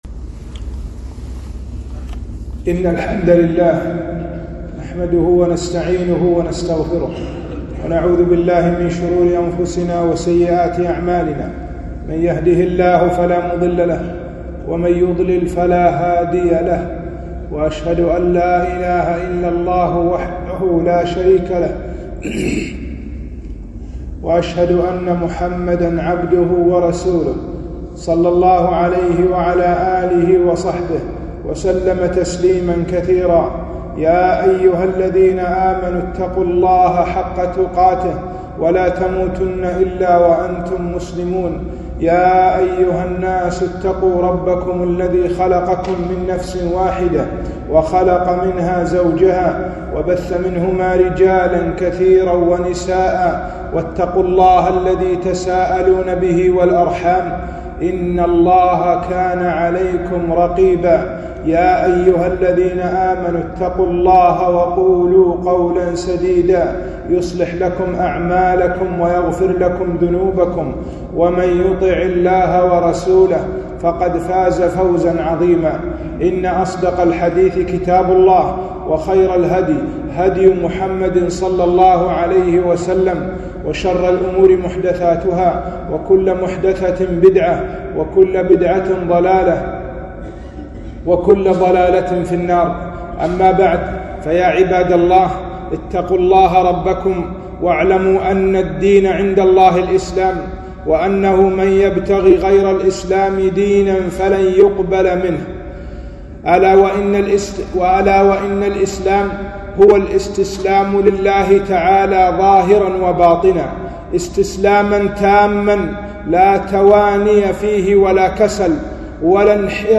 خطبة - حقيقة الدين الإسلام